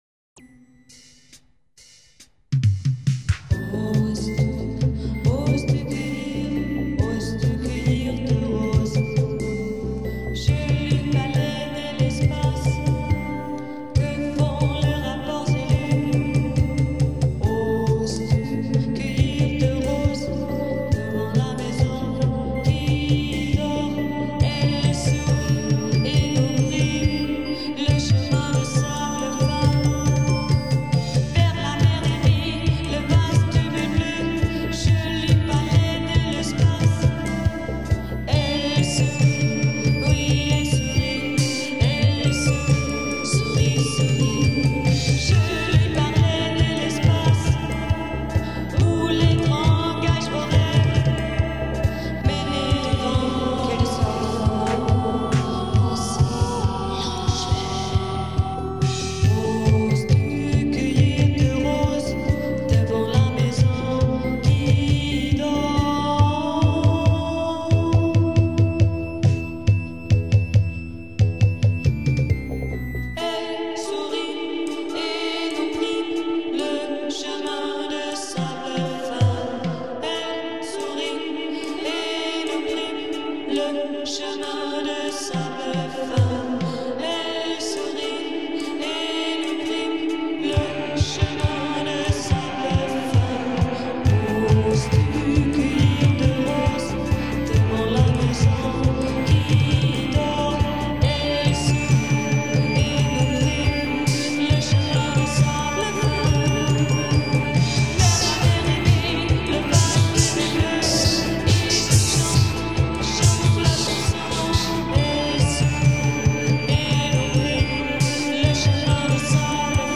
Electric Guitar
Vocals